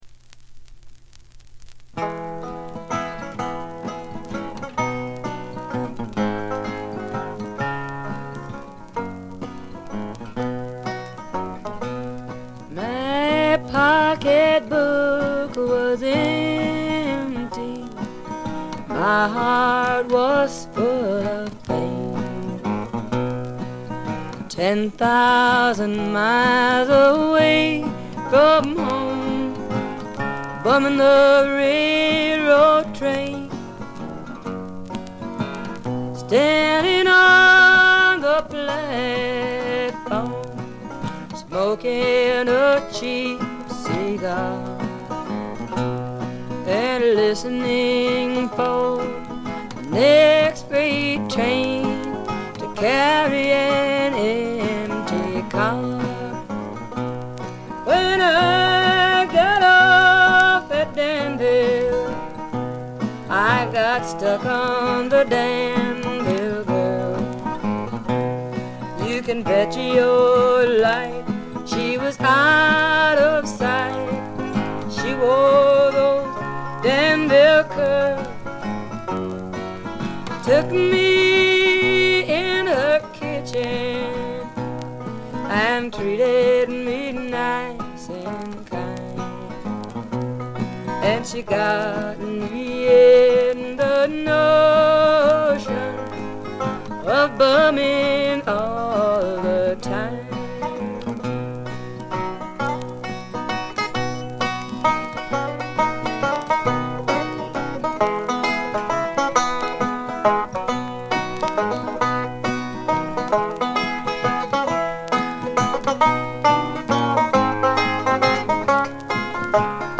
バックグラウンドノイズ、チリプチは常時大きめに出ます。
存在感抜群のアルト・ヴォイスが彼女の最大の武器でしょう。
試聴曲は現品からの取り込み音源です。